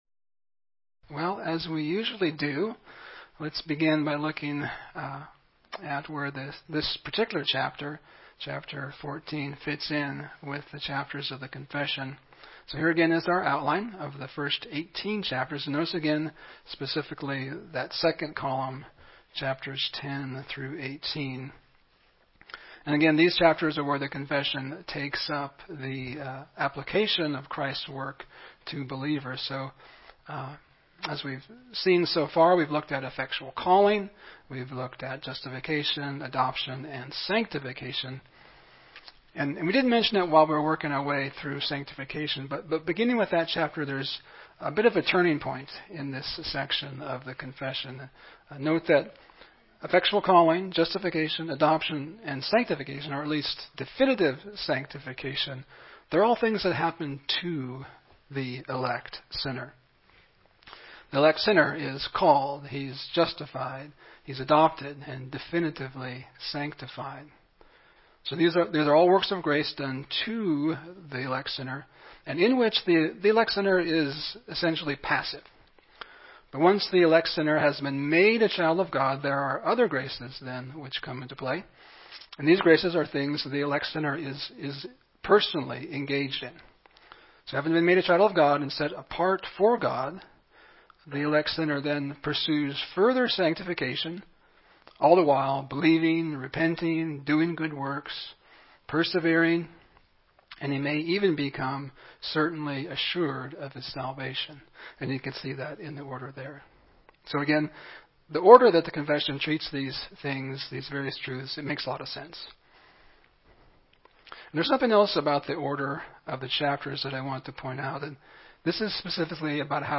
1689 Confession Essentials Service Type: Sunday School « The Discipline of the Lord Not by Might